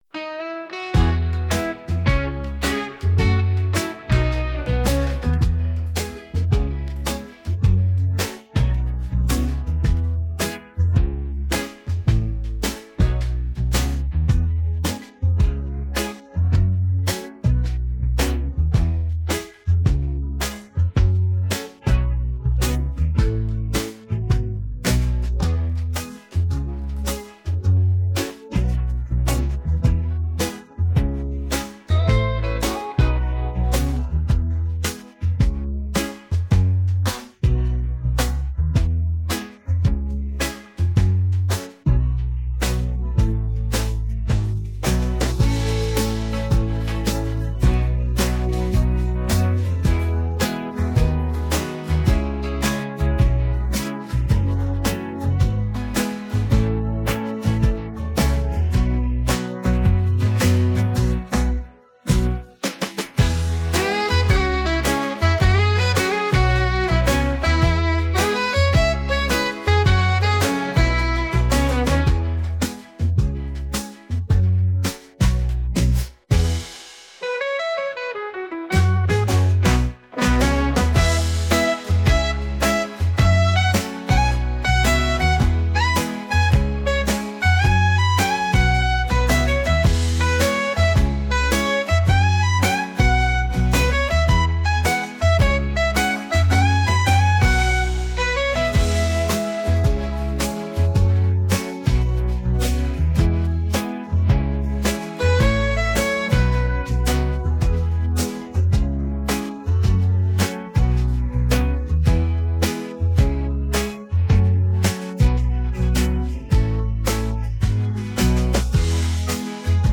Podkład muzyczny tytuł